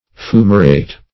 \Fu"ma*rate\